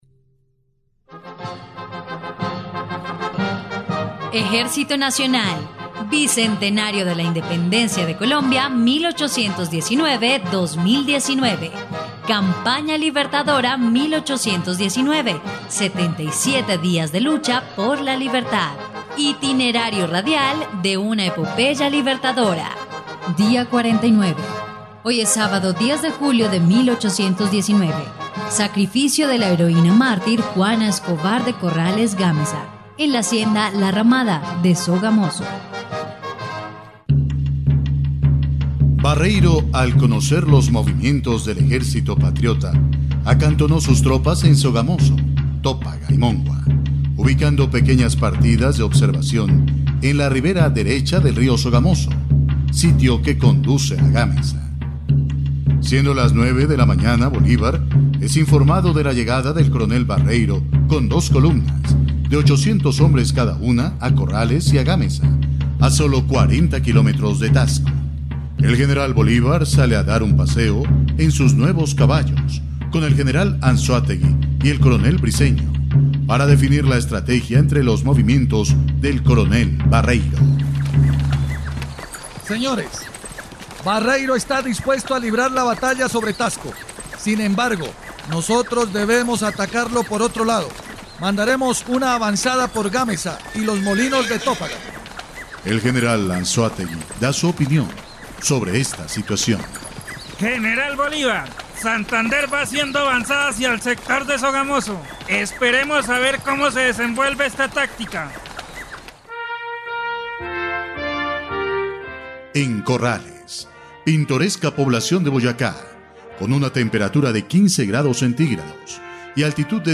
dia_49_radionovela_campana_libertadora.mp3